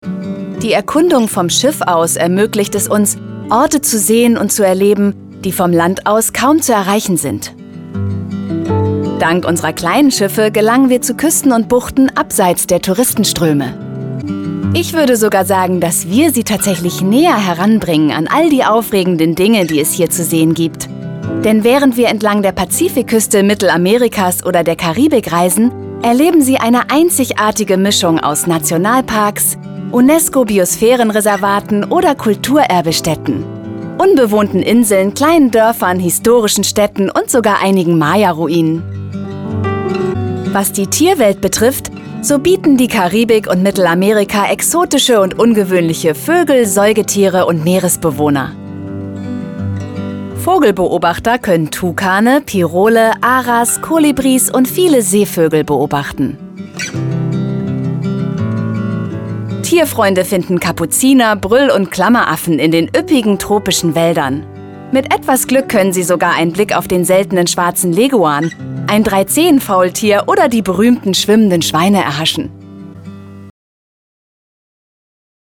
Kommerziell, Zugänglich, Warm, Sanft, Corporate
Audioguide